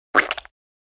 29. Хлюпающий пук
hliupaiushiy-puk.mp3